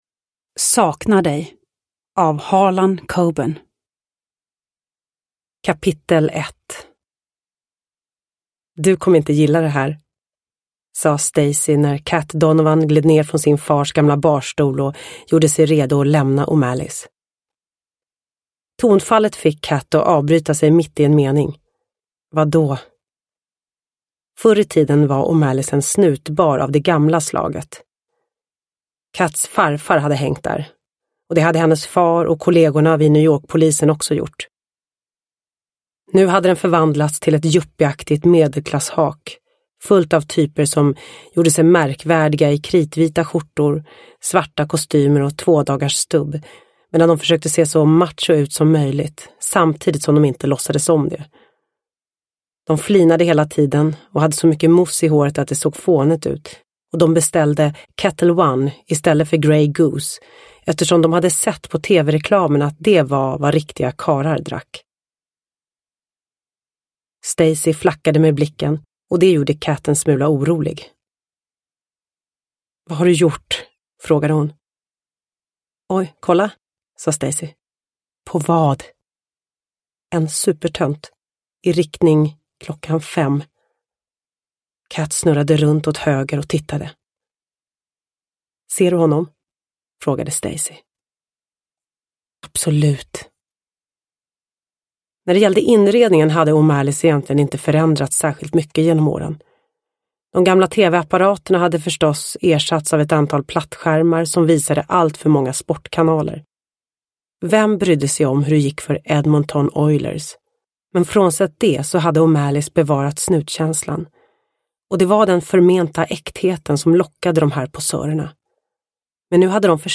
Saknar dig – Ljudbok – Laddas ner
Uppläsare: Lo Kauppi